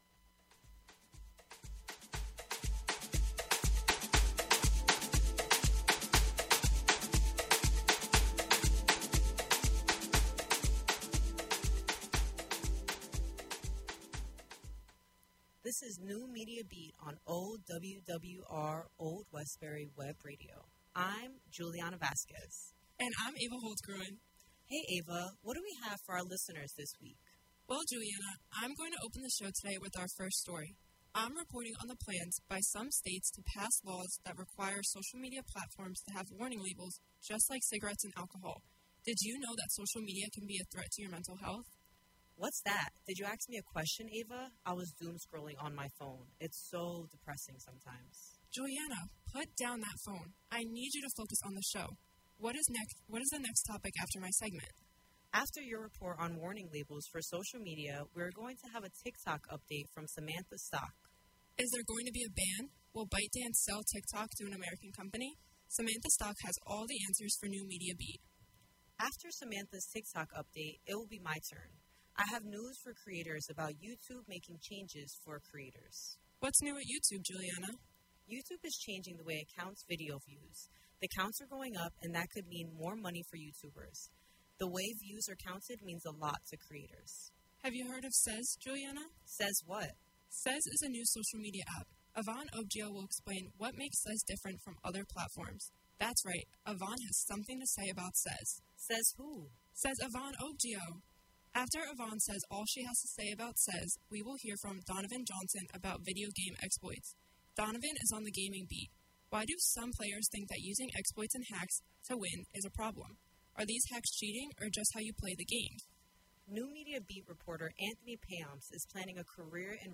The NMB Podcast streams live on Old Westbury Web Radio Thursdays from 12:00-1:00 PM EST. Can’t listen live?